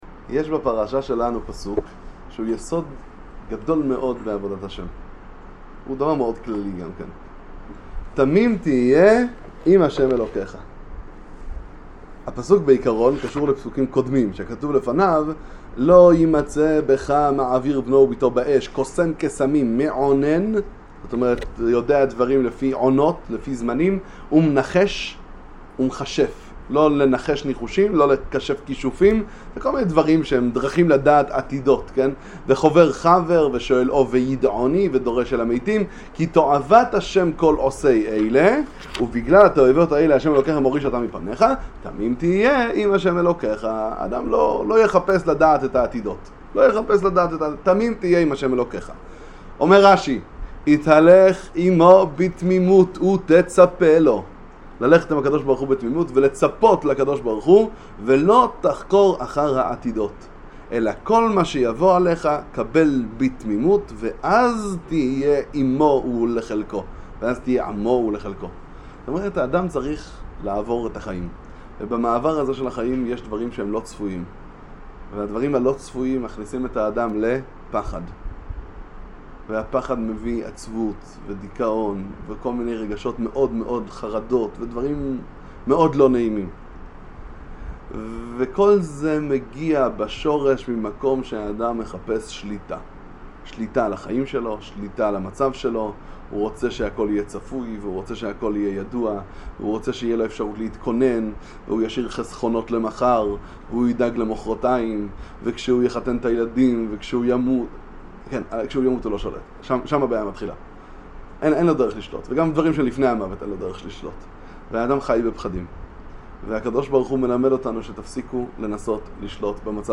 דבר תורה קצר לפרשת שופטים.